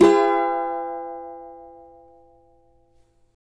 CAVA F#MN  D.wav